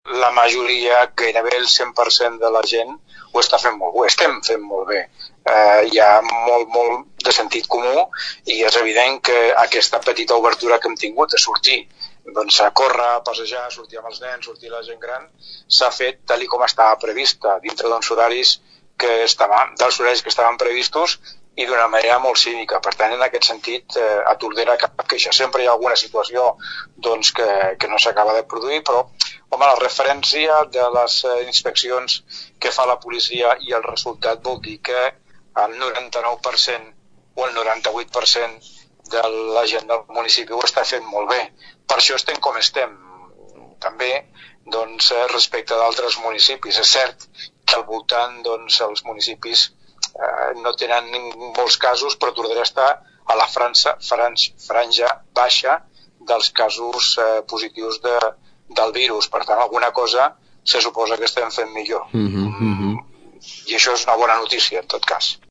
En concret, s’han identificat a 251 persones, els vehicles controlats van ser 76 i s’han interposat  35 denúncies per incompliment del confinament. L’Alcalde de Tordera, Joan Carles Garcia llença un missatge de responsabilitat a seguir les mesures decretades.
alcalde-responsabilitat.mp3